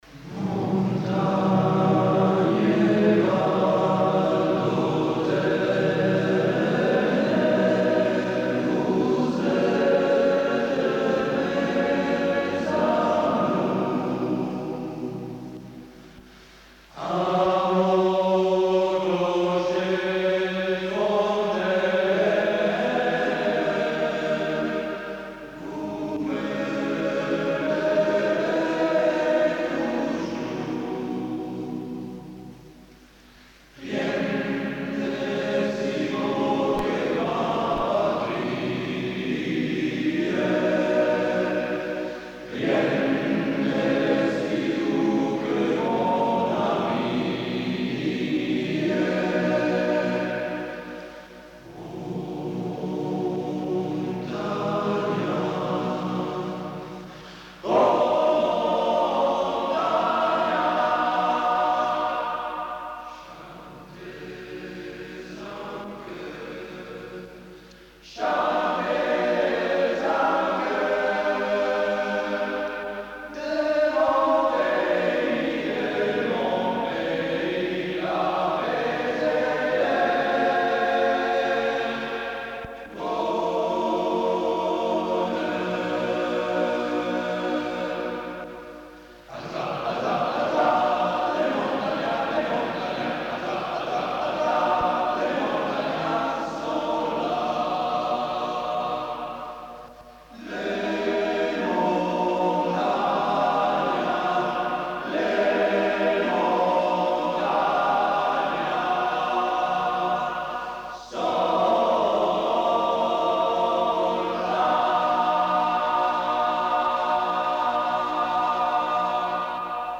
CORO SMALP-103
I CANTI DEL CORO DEL 103°